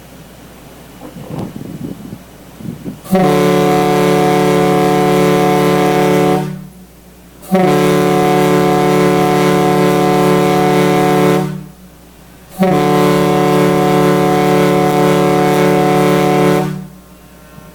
SS-Badger-Horn.mp3